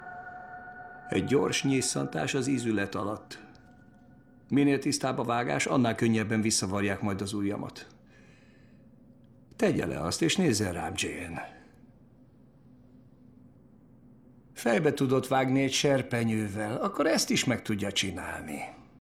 A kis kutyafülűben fantasztikusan játszott, és emlékszem, az első részben több ízben is vissza kellett néznem bizonyos jeleneteket, mert nem hittem el, hogy őt hallom, annyira elváltoztatta a hangját.